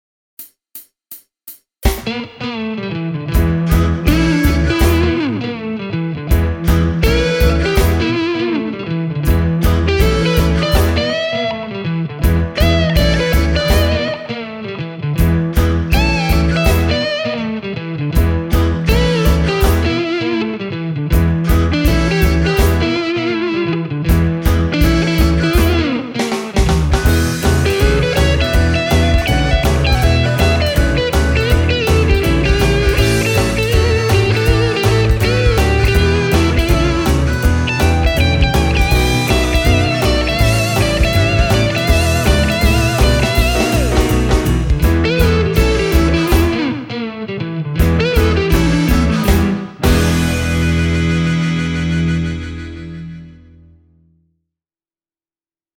You get that classic clean tone with that sweet mid-range ”attitude”. A clean tone that is lively, but never glassy or brittle.
Here’s a Gibson Les Paul Junior on its own: